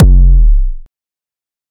EDM Kick 24.wav